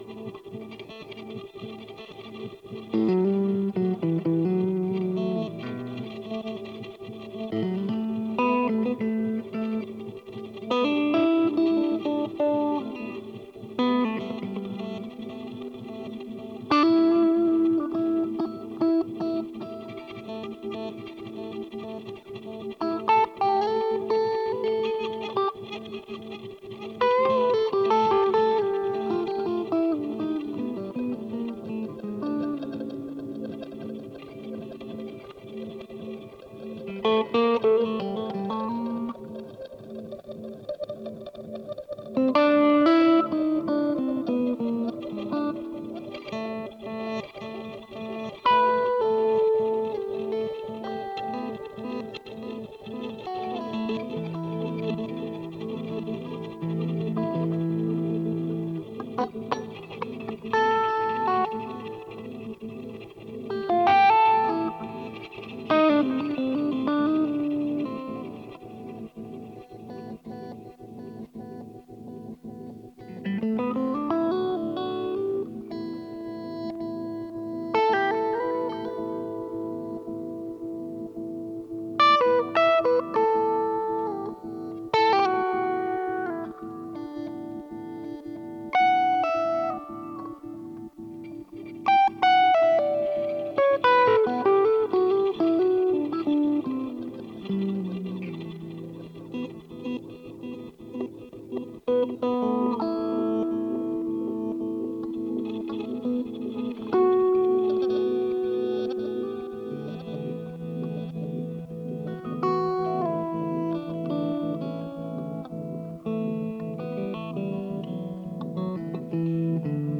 Ganz ohne das Background Verzerrte wäre es für mich finest pure sound.
zu der dann reaktiv eine zweite Spur entstand.